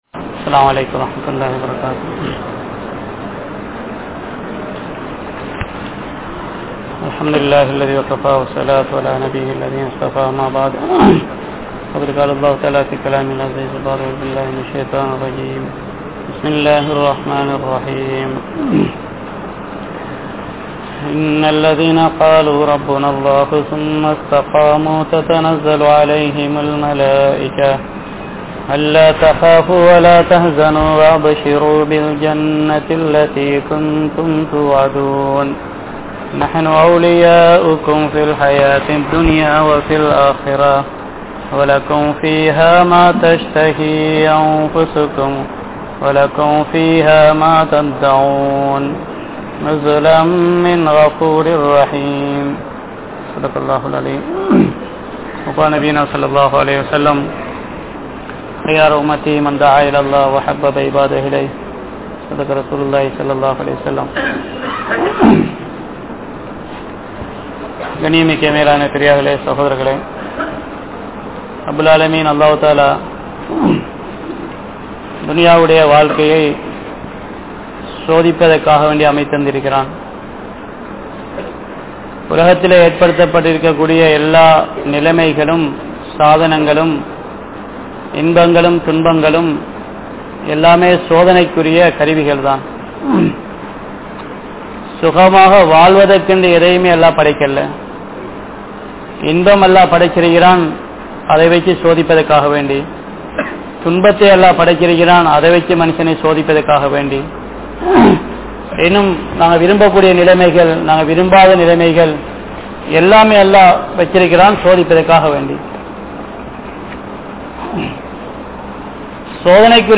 Dhawwah Entral Enna? (தஃவா என்றால் என்ன?) | Audio Bayans | All Ceylon Muslim Youth Community | Addalaichenai